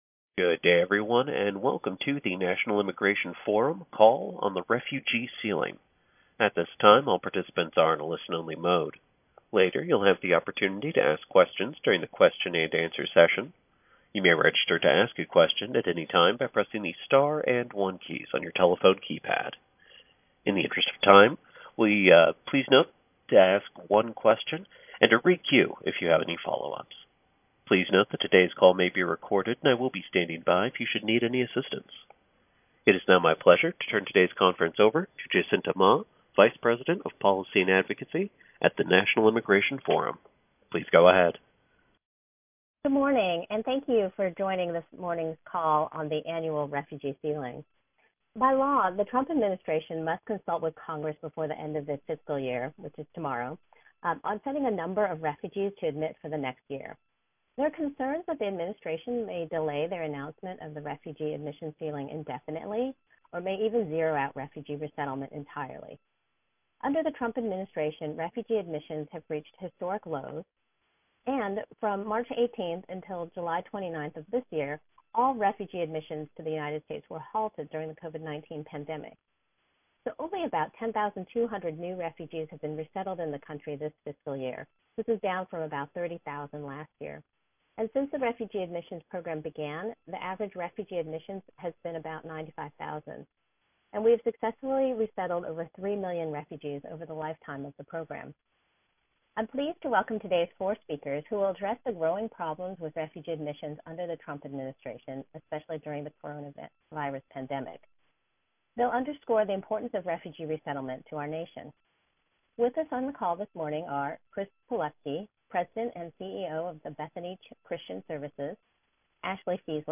WASHINGTON, D.C. — Leading faith, business and national security voices urged the Trump administration to commit to accepting more refugees in 2021 on a press call today.